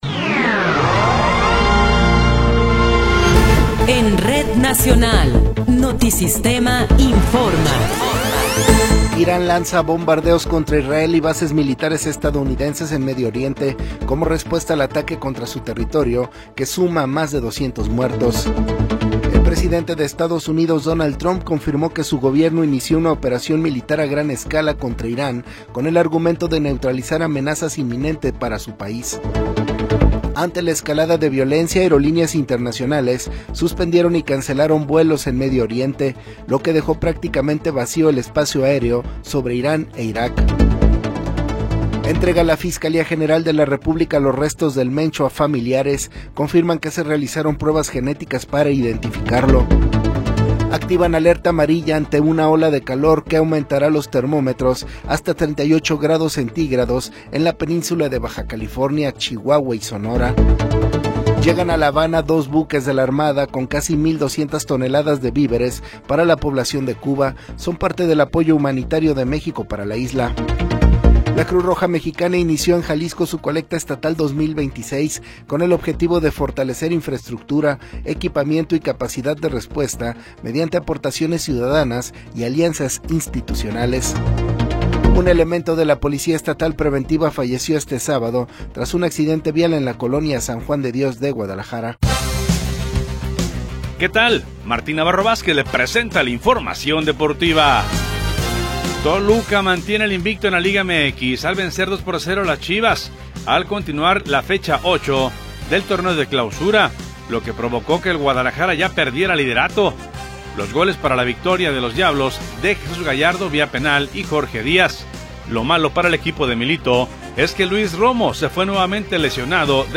Noticiero 21 hrs. – 28 de Febrero de 2026
Resumen informativo Notisistema, la mejor y más completa información cada hora en la hora.